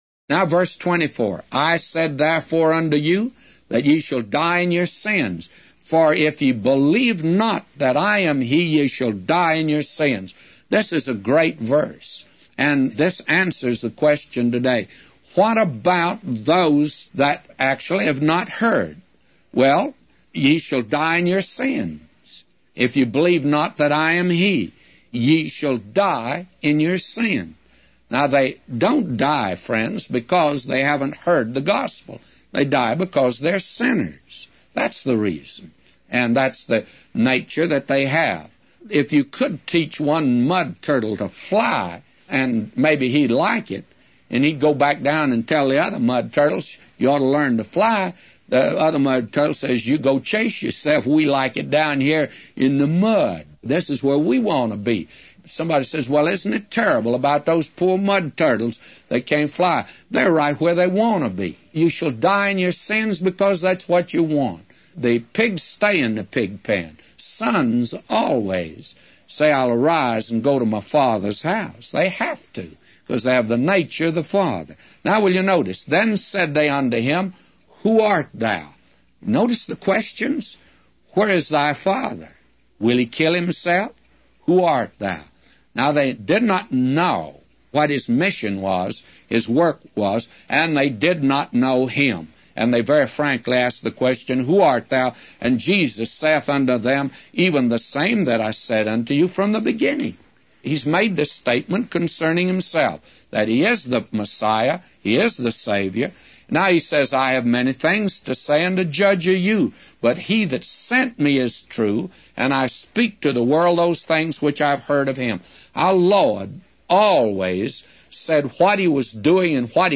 Morning Bible Reading - 1 Chronicles 7